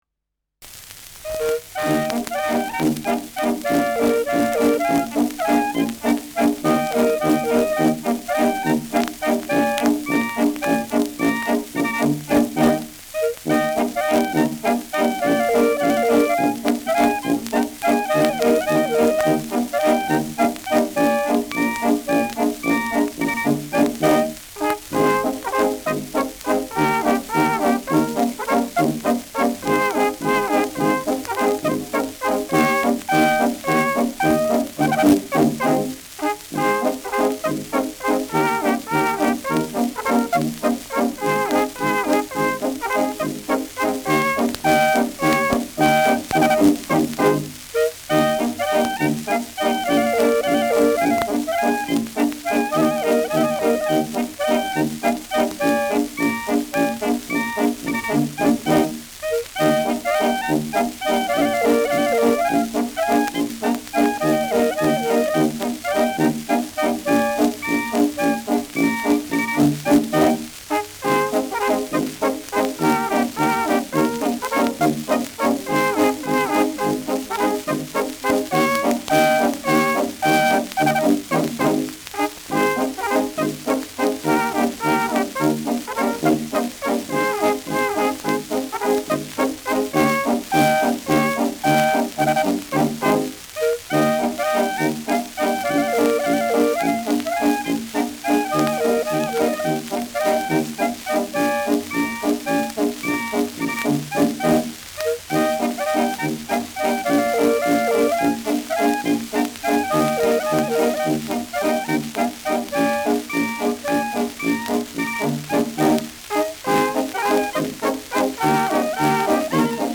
Schellackplatte
präsentes Rauschen : präsentes Knistern : abgespielt
Kapelle Bosl, Falkenstein (Interpretation)